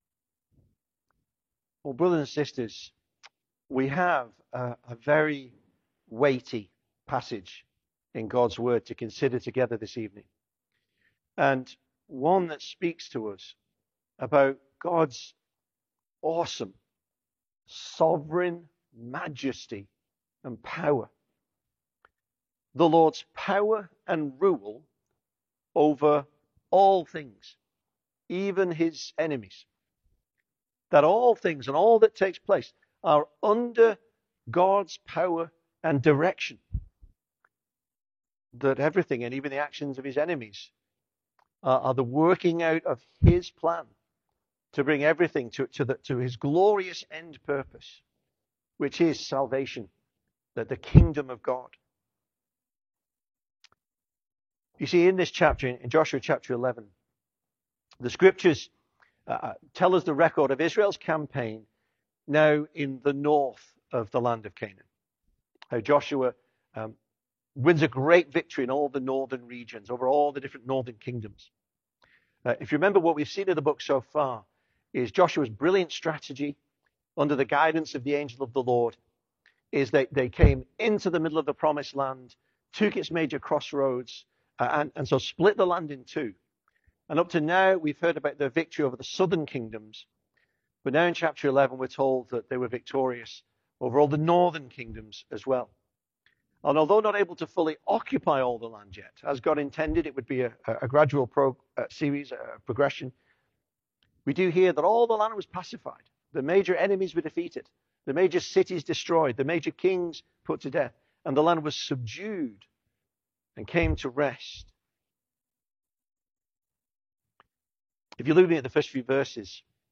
2026 Service Type: Sunday Evening Speaker